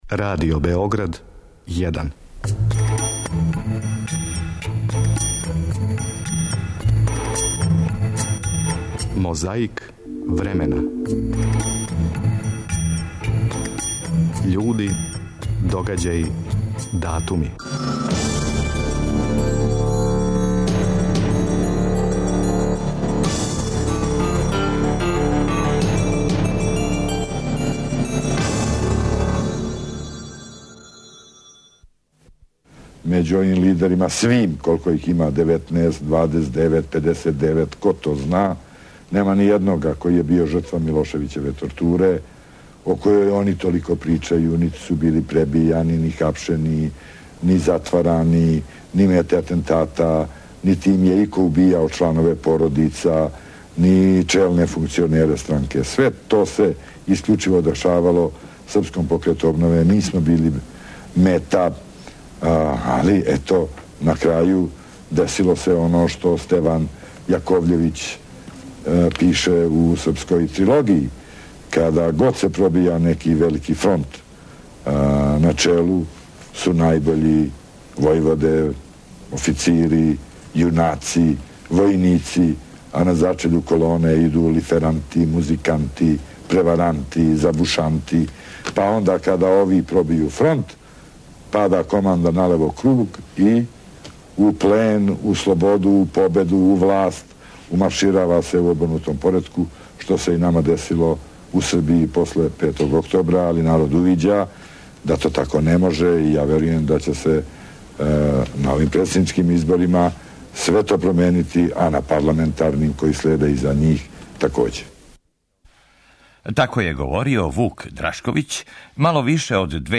Чућете како је говорио новембра 2002. године.
Захваљујући тонском архиву Радио Београда, сећамо се сећања на тај дан.